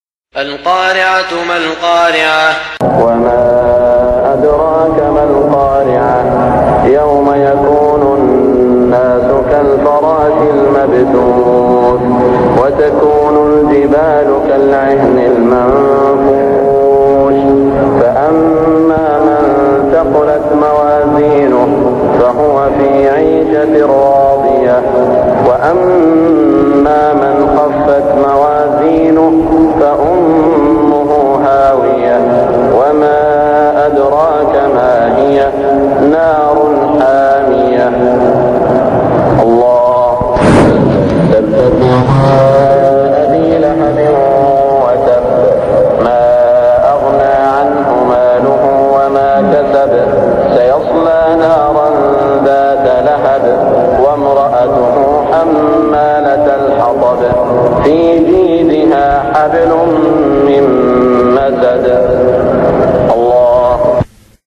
صلاة المغرب 1416هـ سورتي القارعة و المسد > 1416 🕋 > الفروض - تلاوات الحرمين